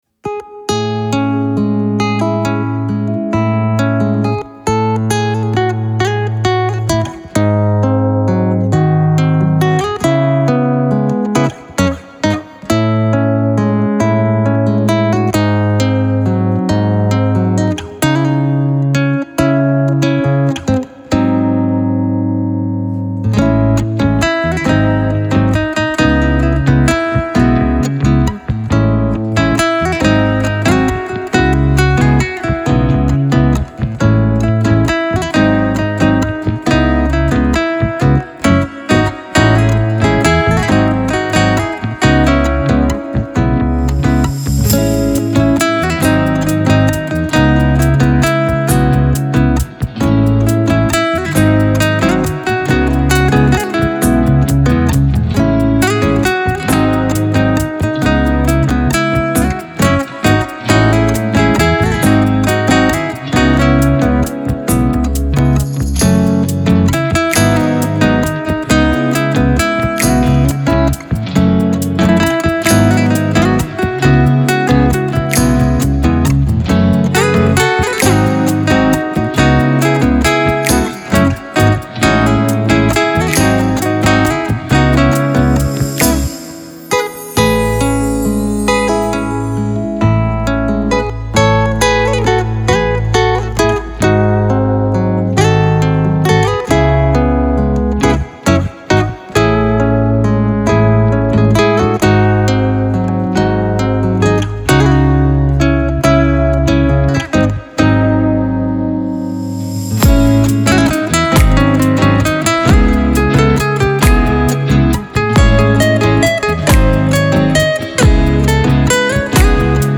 موسیقی کنار تو
پاپ , گیتار , موسیقی بی کلام